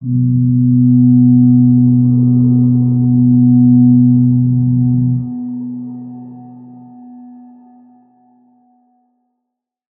G_Crystal-B3-mf.wav